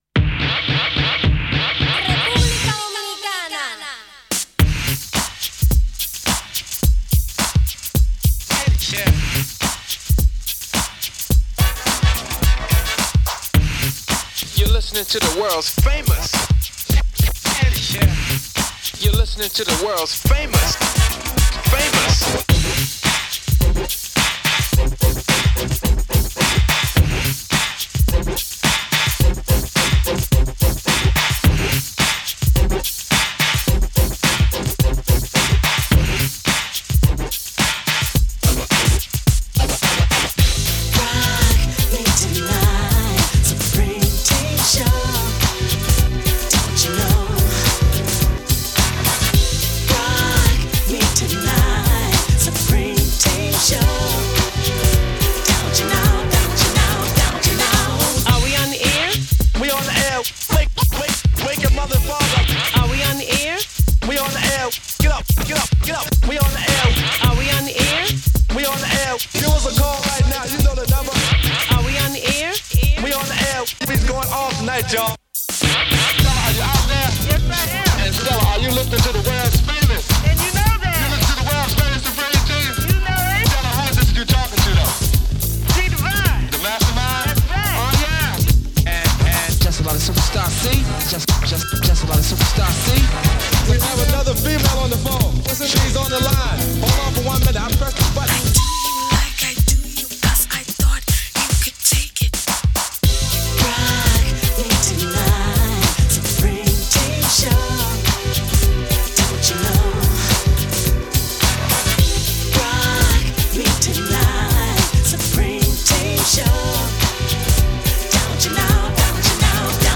Remember when Hip-Hop was fun?